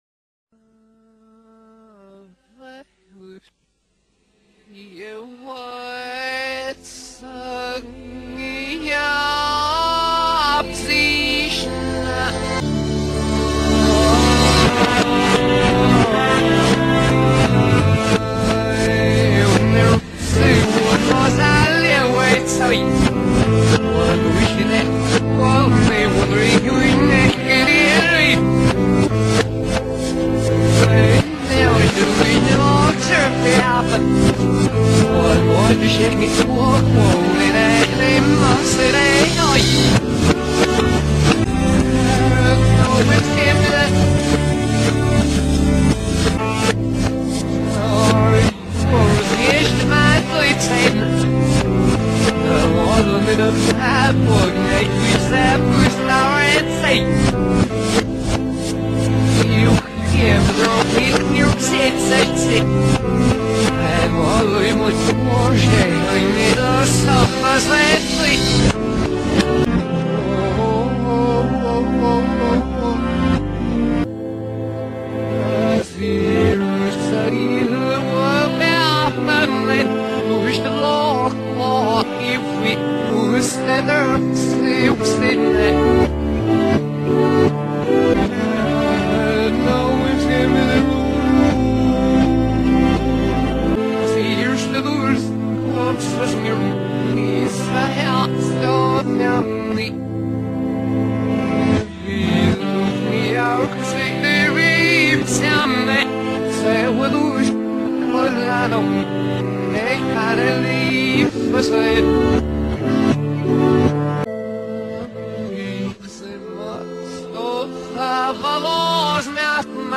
in reverse